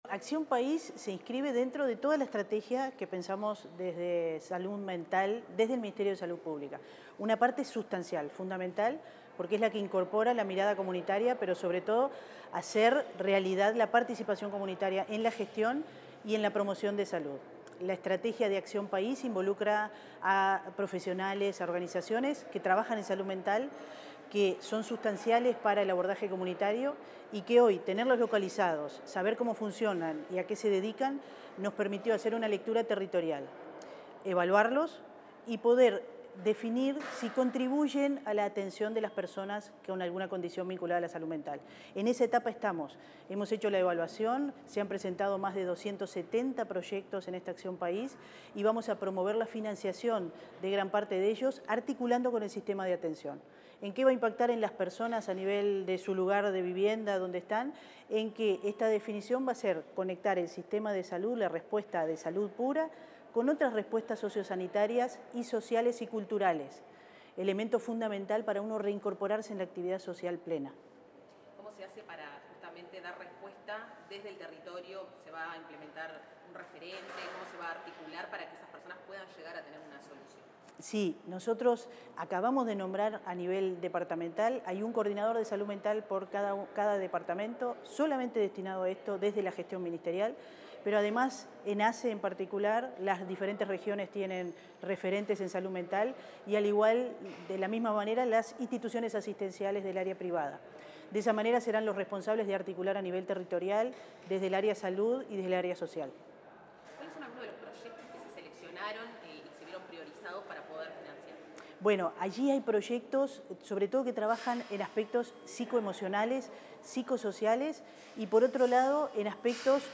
Declaraciones de la directora general de Salud, Fernanda Nozar